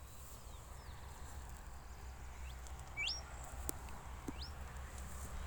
Nome científico: Nothoprocta pentlandii
Nome em Inglês: Andean Tinamou
Detalhada localização: Ruta 307 entre Tafí del Valle y El Infiernillo
Condição: Selvagem
Certeza: Gravado Vocal
Inambu-silbon.mp3